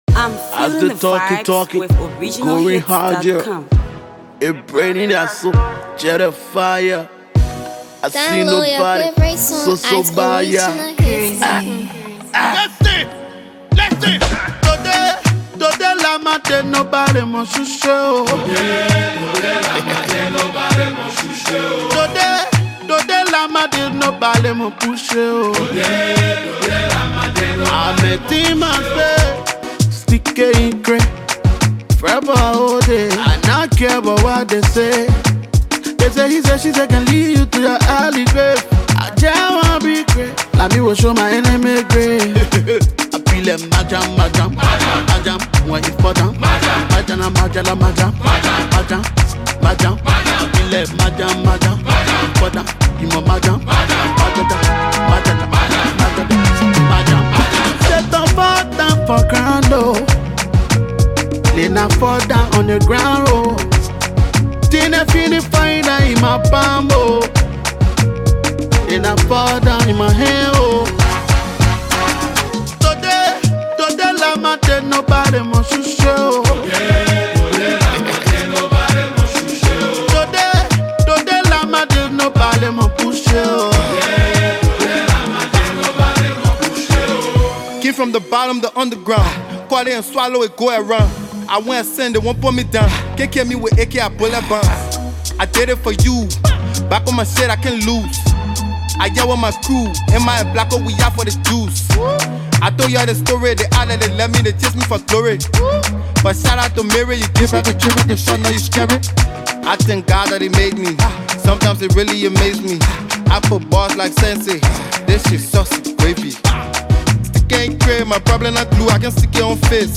Trap
spitting raw bar